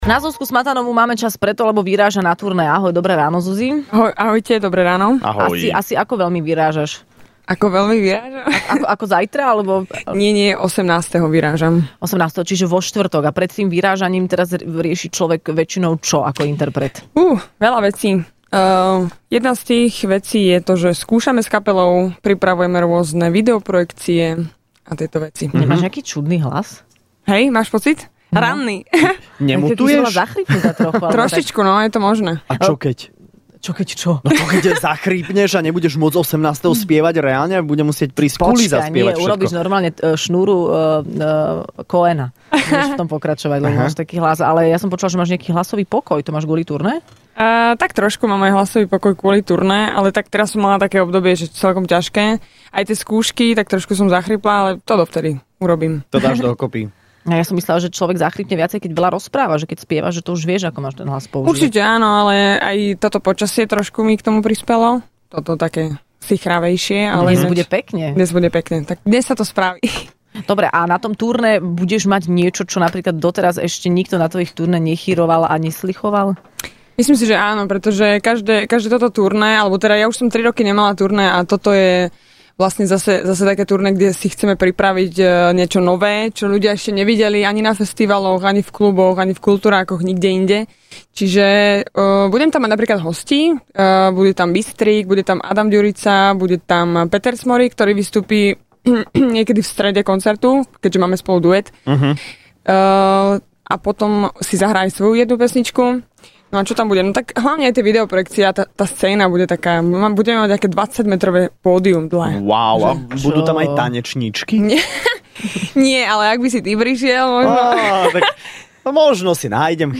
Zuzka Smatanová I: Zuzka Smatanová prišla do Fun rádia porozpávať o svojom pripravovanom turné, ktoré sa začína už 18.11